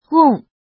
怎么读
wèng
weng4.mp3